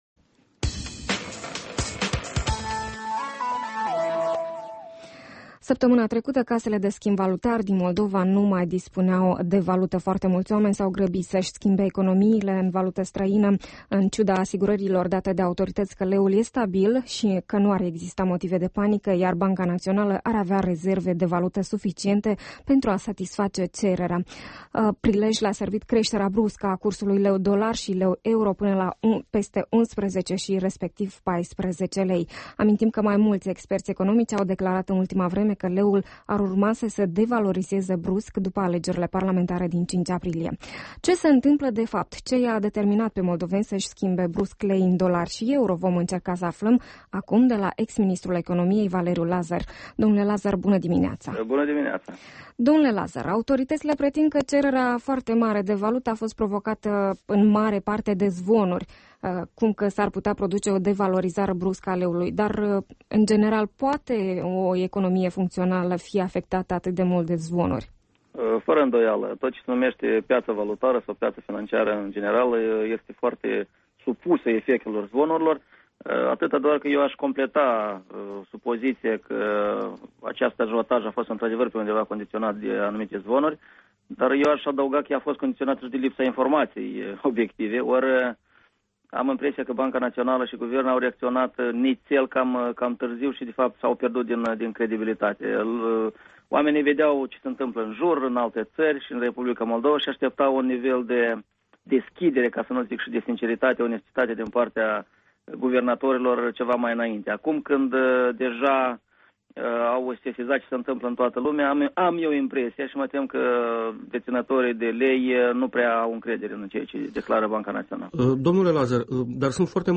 Interviu cu Valeriu Lazăr, fost ministru al economiei